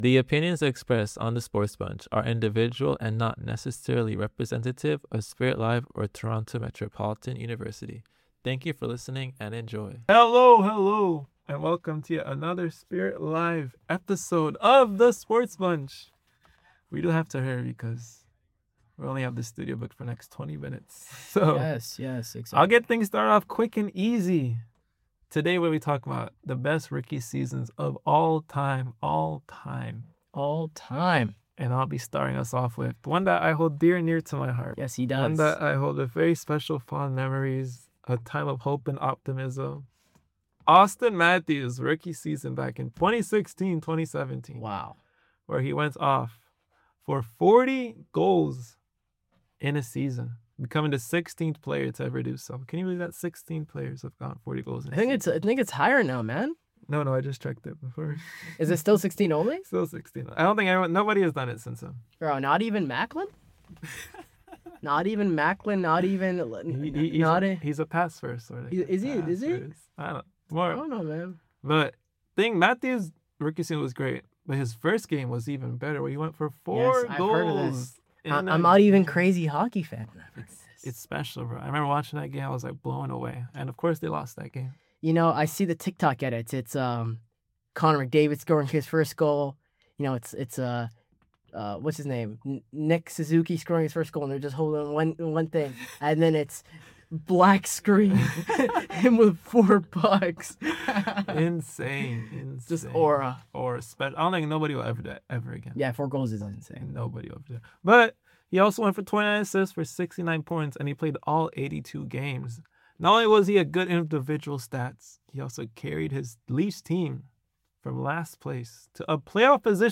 SportsBunch is a student-led talk show where bold opinions, sharp analysis, and real conversations bring the world of sports to life.